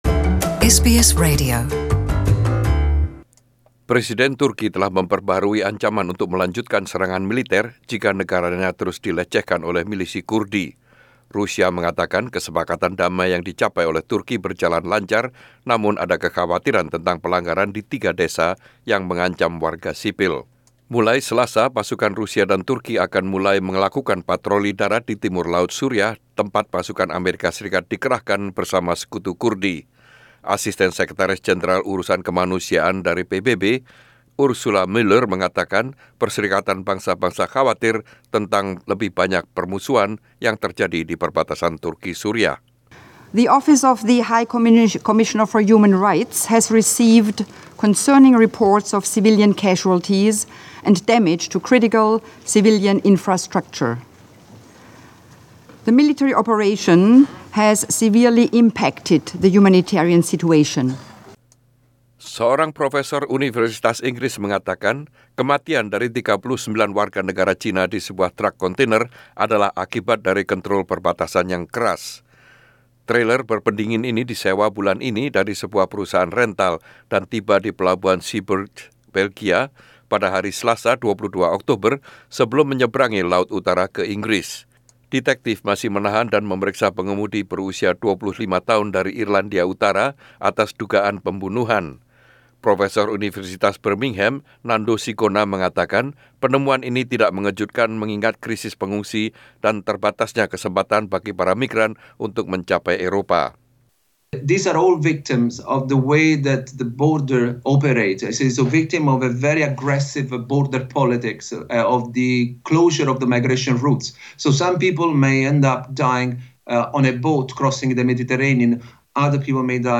SBS Radio News in Indonesian - 25/10/2019